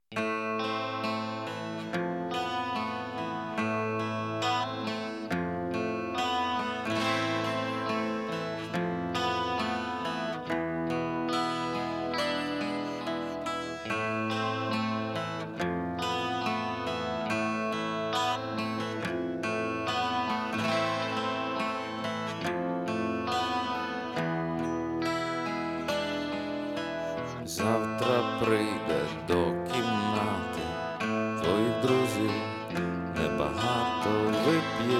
Жанр: Украинские